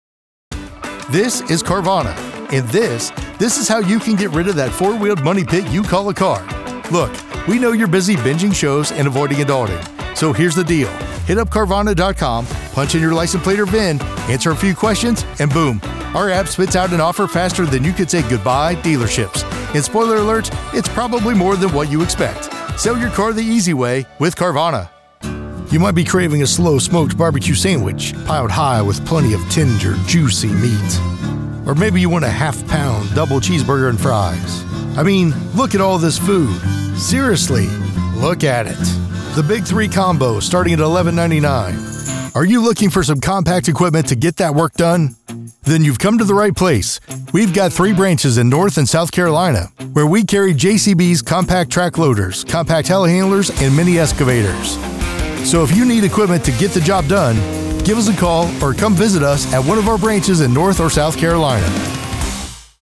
🎙 American Male Voice Over | Versatile, Professional & Broadcast-Ready
Commercial Voice Over Demo
English - Midwestern U.S. English
Middle Aged
Sennheiser MKH 416 microphone — the gold standard for voice-over recording